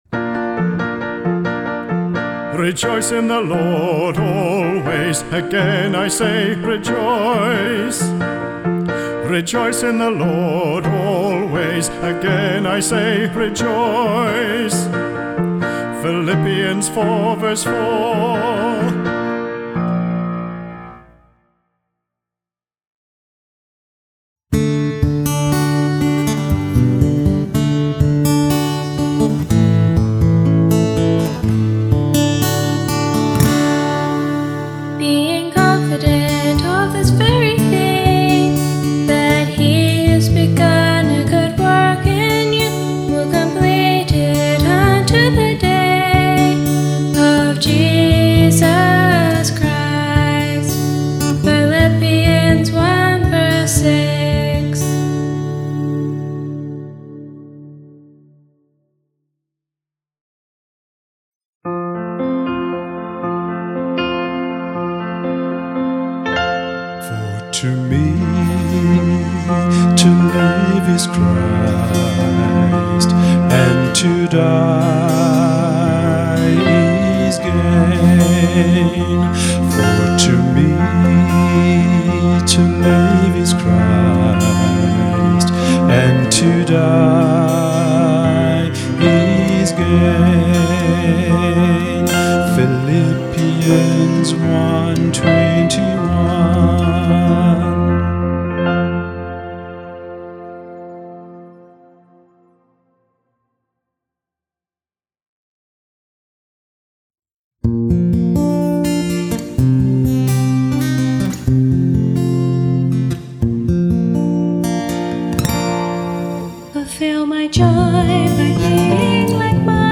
Vocalist
Cello
Guitar
Piano